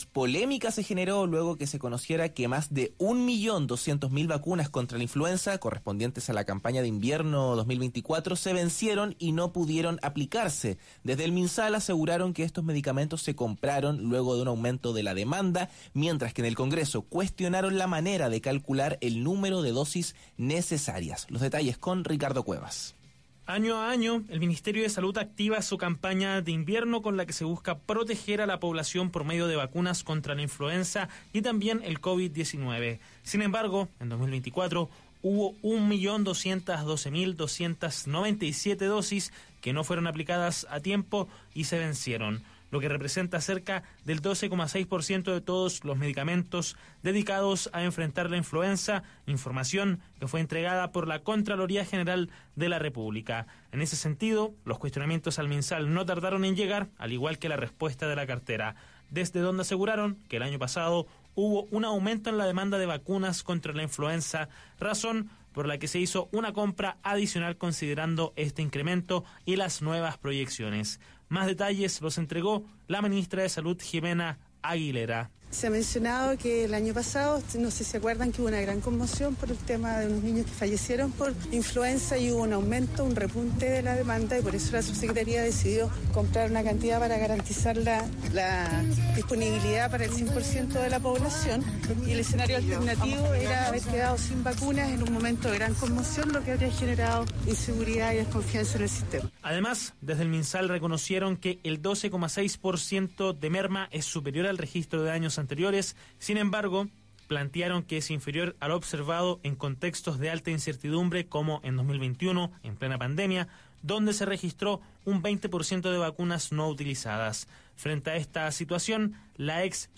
La directora ejecutiva de CIPS UDD, Paula Daza, habló con Radio Agricultura sobre la reciente polémica en torno a la campaña de vacunación contra la influenza del Ministerio de Salud (Minsal), tras revelarse que más de 1.2 millones de dosis correspondientes a la campaña de invierno 2024 se vencieron sin ser aplicadas, lo que representa aproximadamente el 12.6% del total de vacunas disponibles.